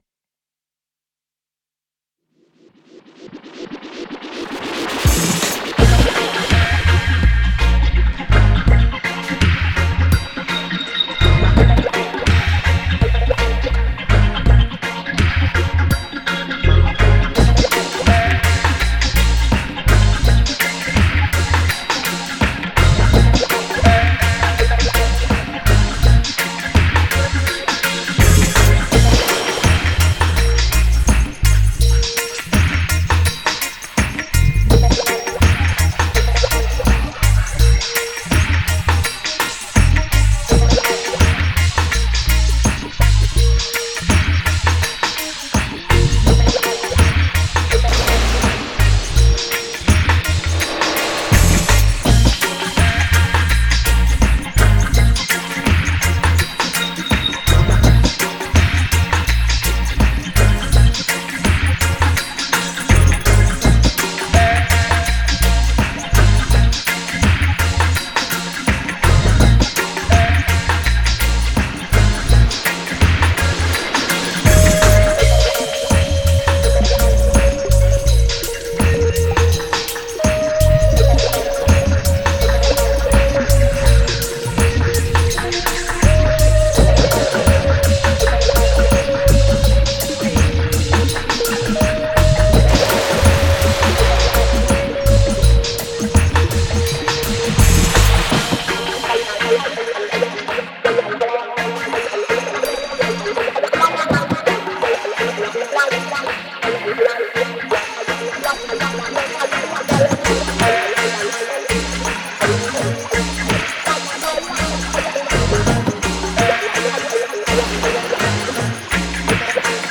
Genre:Dub
デモサウンドはコチラ↓
Genre(s):  Roots Dub / Reggae
Tempo Range: 83bpm
Key: Bbm